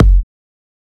edm-kick-20.wav